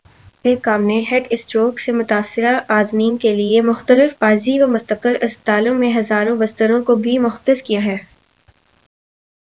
Spoofed_TTS/Speaker_16/15.wav · CSALT/deepfake_detection_dataset_urdu at main
deepfake_detection_dataset_urdu / Spoofed_TTS /Speaker_16 /15.wav